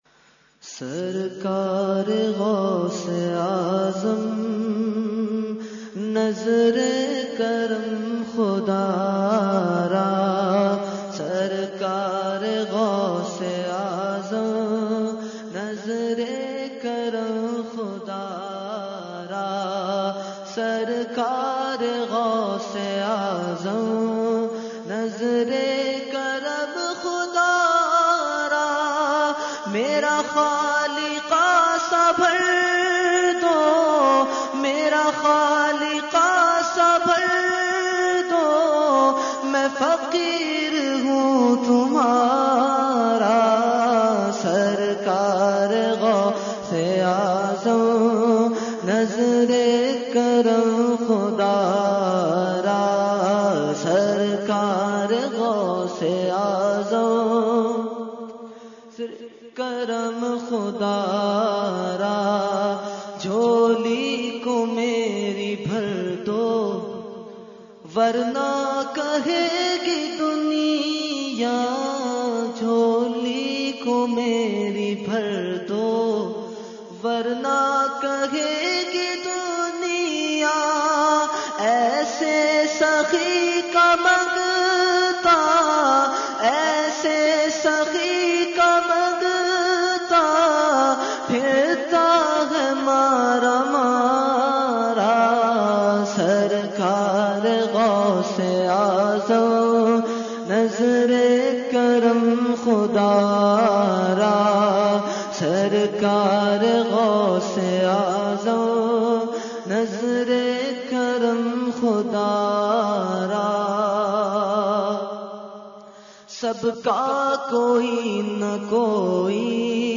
Category : Manqabat | Language : UrduEvent : 11veen Shareef 2018-2